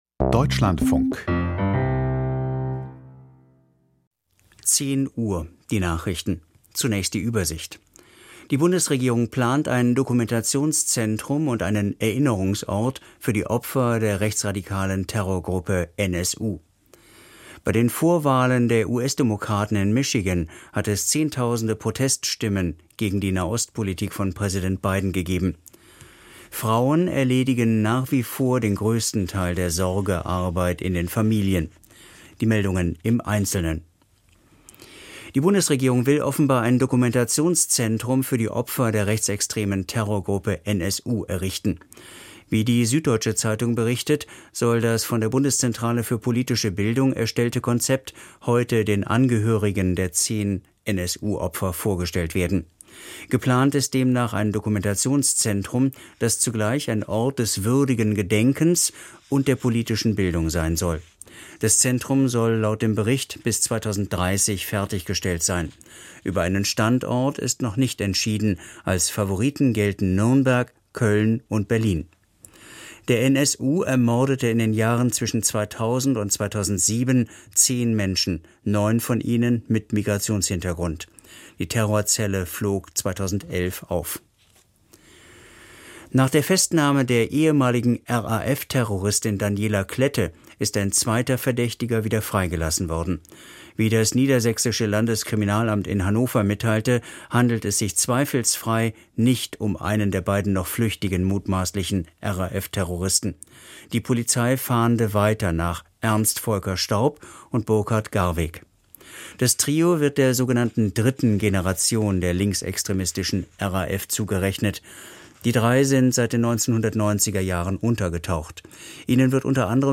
Wie positioniert sich Mitteleuropa zur Ukraine? Gespräch